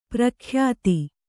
♪ prakhyāti